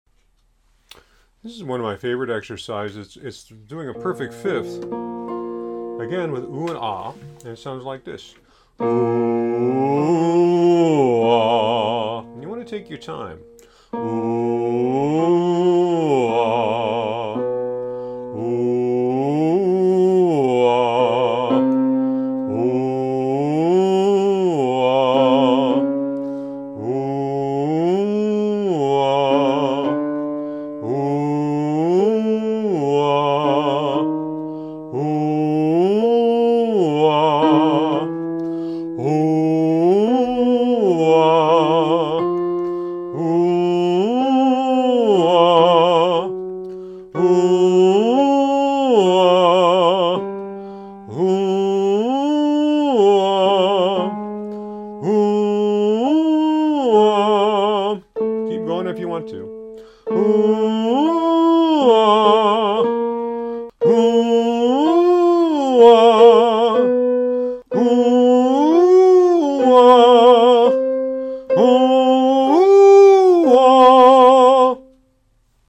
Warm-up Exercises
vocalise-5.mp3